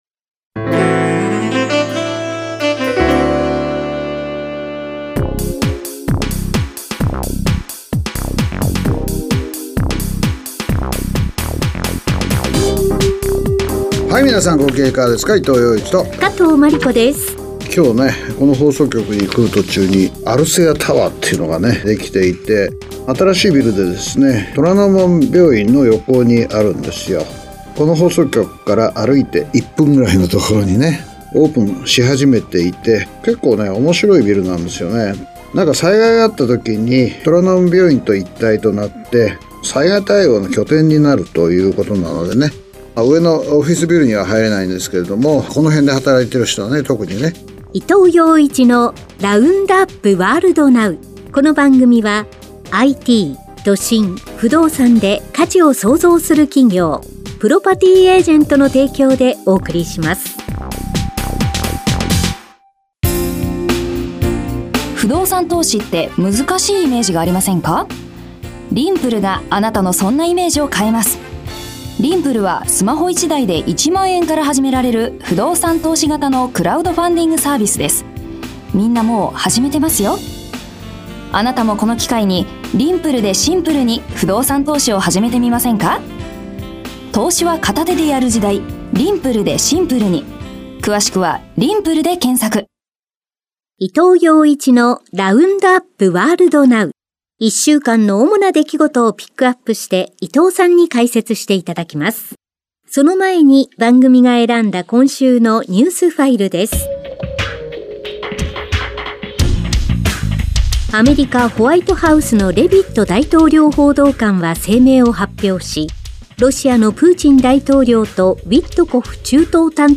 … continue reading 443 episoder # ニューストーク # ニュース # ビジネスニュース # NIKKEI RADIO BROADCASTING CORPORATION